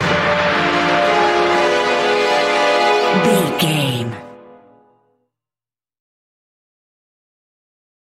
In-crescendo
Thriller
Aeolian/Minor
tension
ominous
eerie
orchestra
string
brass
Horror Synths
atmospheres